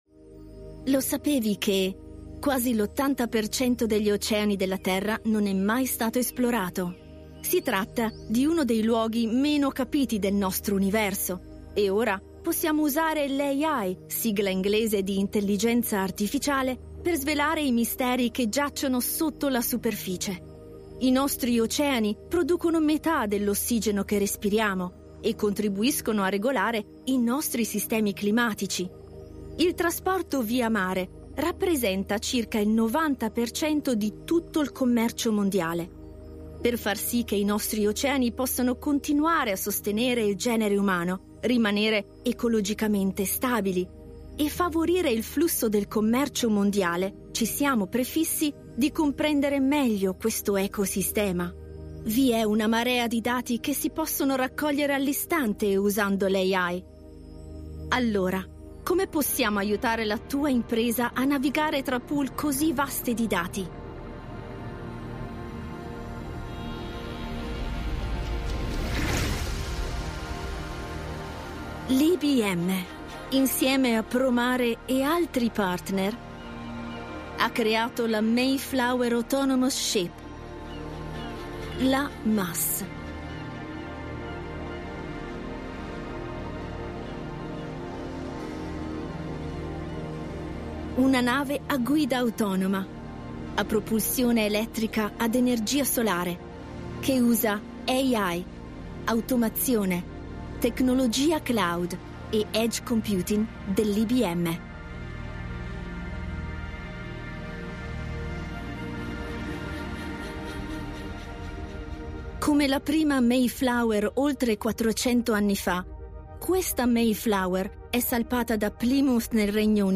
Explainer Videos
I am a native Italian voice over artist.
My voice is young, fresh and energetic.
I can record in Italian with a neutral accent or English with great diction and a great soft Italian accent.
Microphone: Rode NT1A
Mezzo-SopranoSoprano